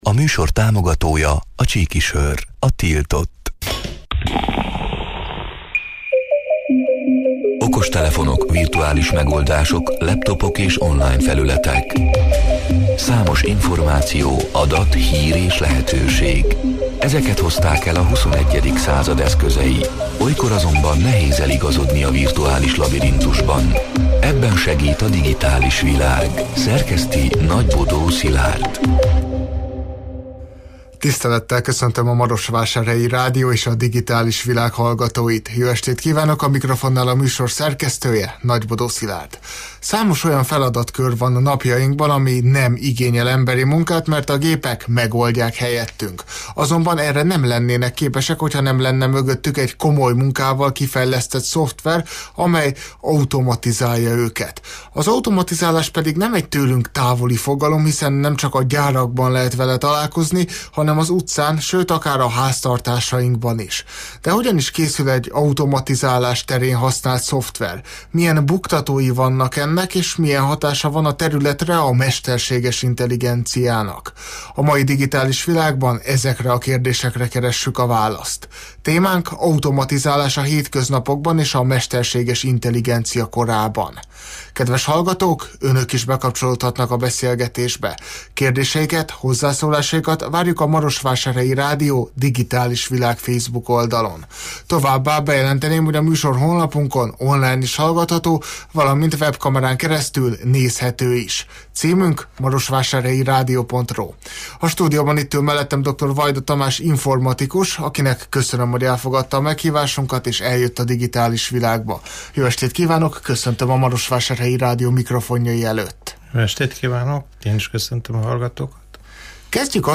A Marosvásárhelyi Rádió Digitális Világ (elhangzott: 2025. május 13-án, kedden este nyolc órától élőben) c. műsorának hanganyaga: